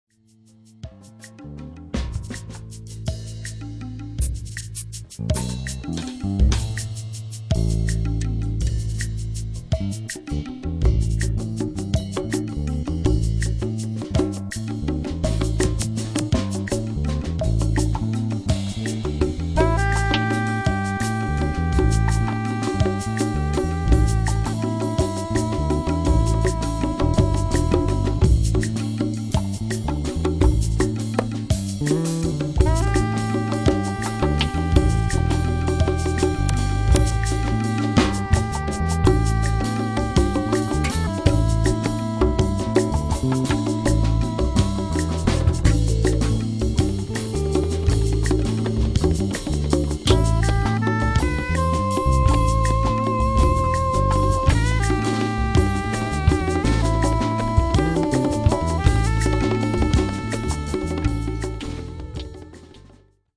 In short, it grooves as it moves.